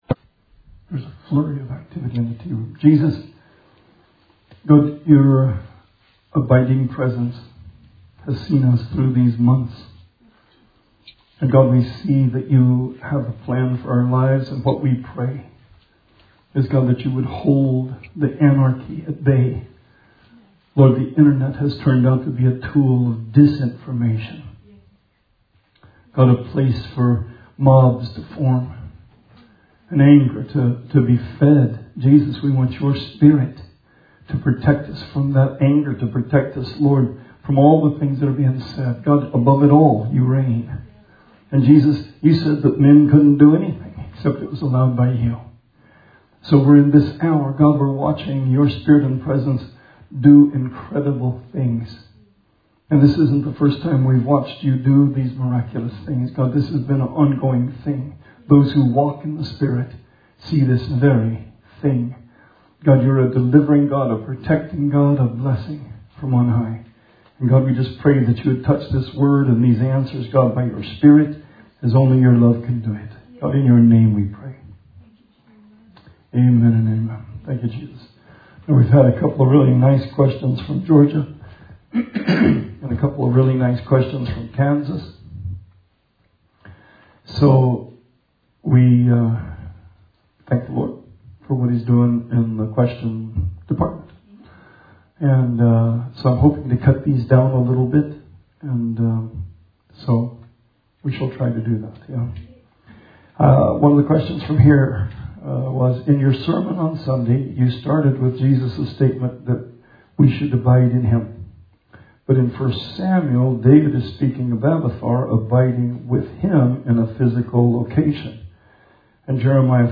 Bible Study 5/27/20